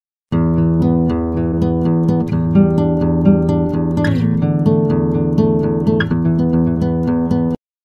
A.26 Time Stretch
n.b. All’interno della traccia sono contenute variazioni di Time.